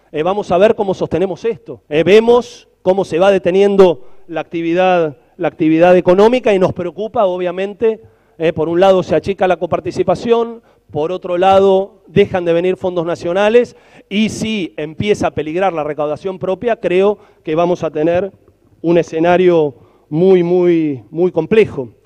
En la apertura de las sesiones ordinarias del Concejo, el intendente de Luján, Leonardo Boto, ofreció este martes un dramático panorama por el freno de la obra pública, la incertidumbre sobre el giro de fondos y el incremento de la demanda alimentaria, de medicamentos y vivienda.